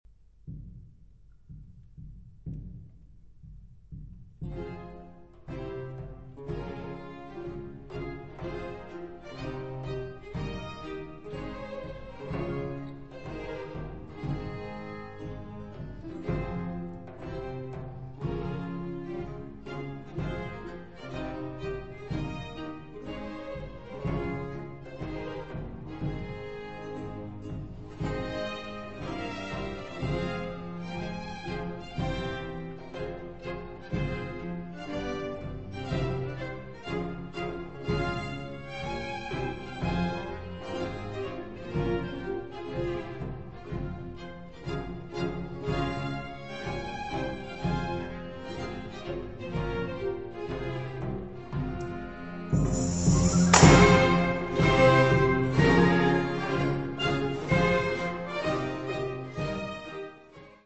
Een barokorkest speelde de muziek.